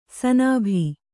♪ sanābhi